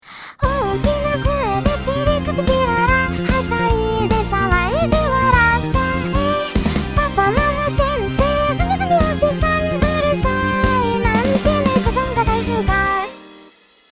ベースとドラムもリズムを調整